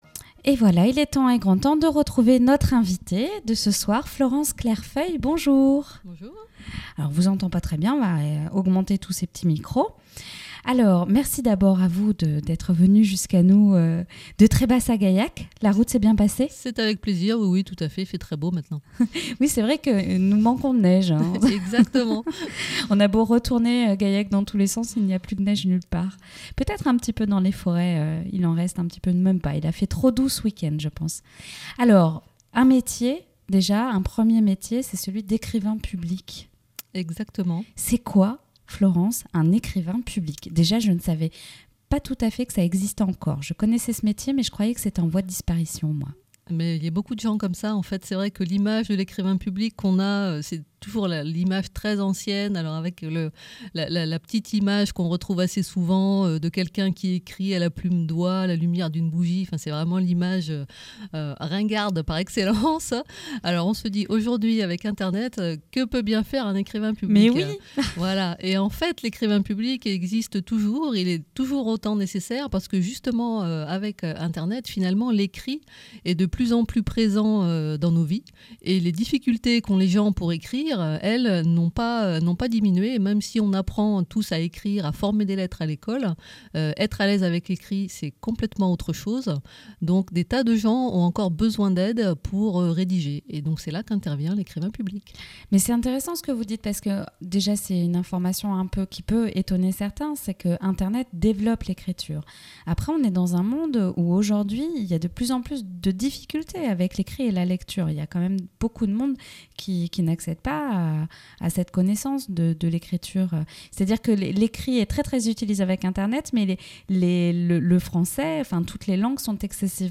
Vous voulez entendre une biographe parler de son métier ? Écoutez-la lors de ses passages à la radio.